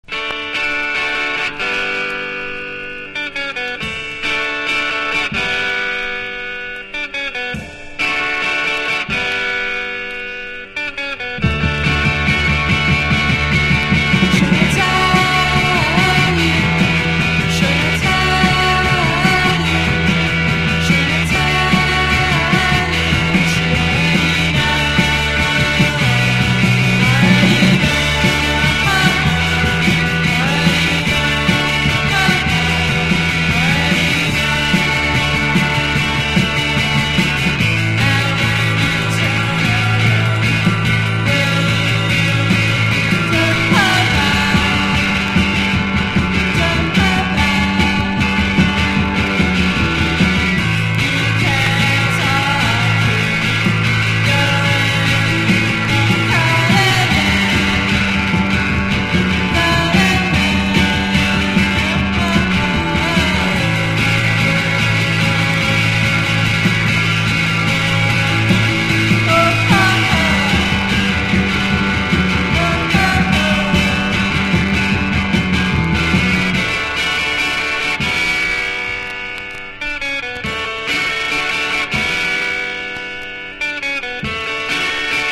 1. 00S ROCK >
# GARAGE ROCK